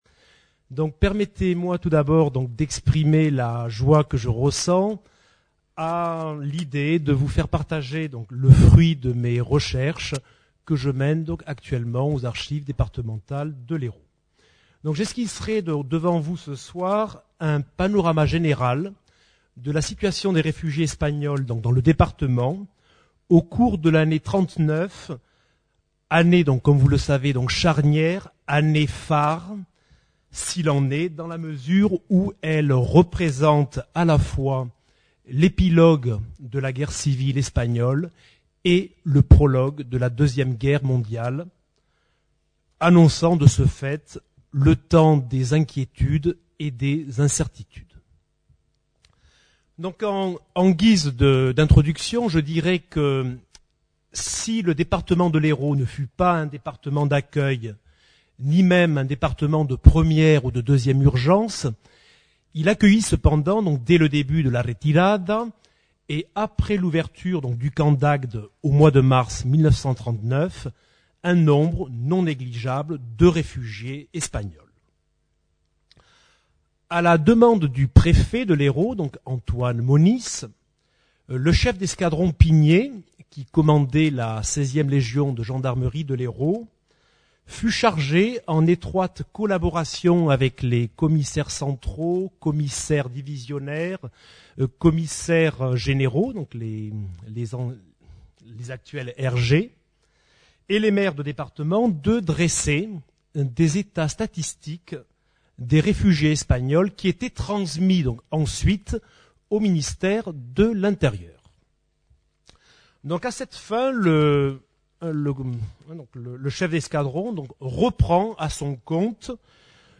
Rencontre littéraire